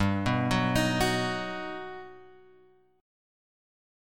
G+M7 chord {3 2 4 x 4 2} chord